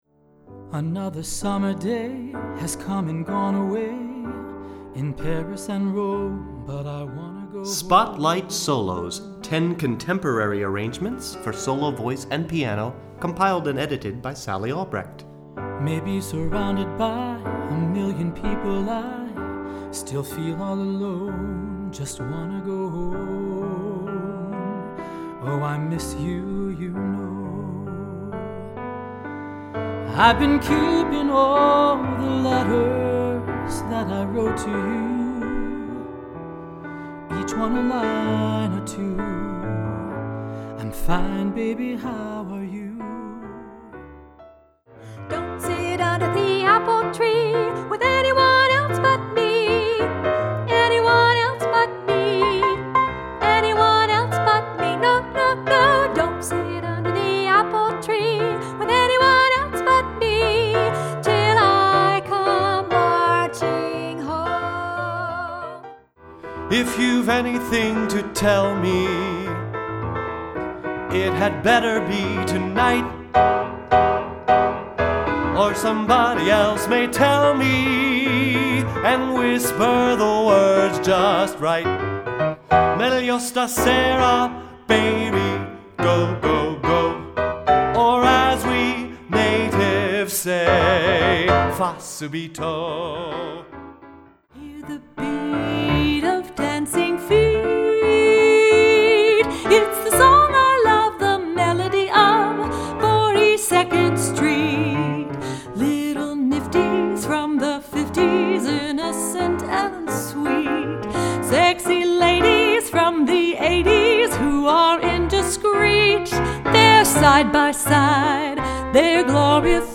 Voicing: Medium High Voice